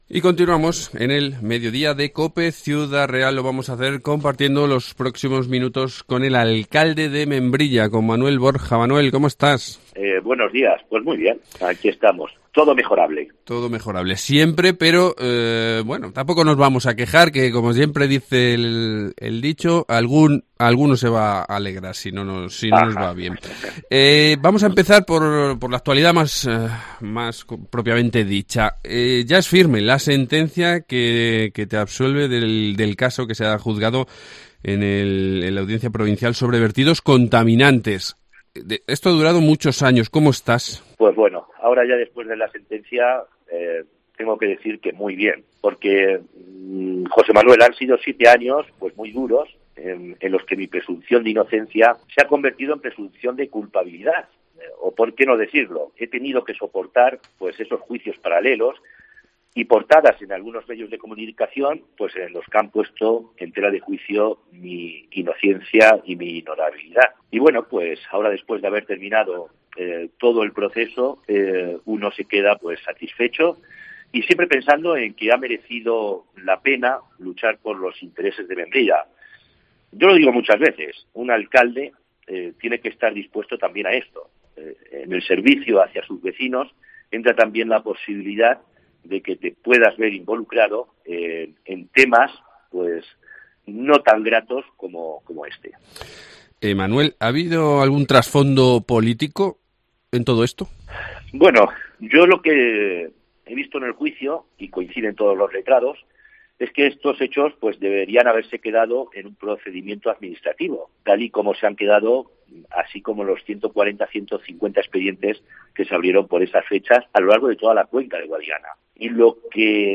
Manuel Borja, alcalde de Membrilla
Entrevista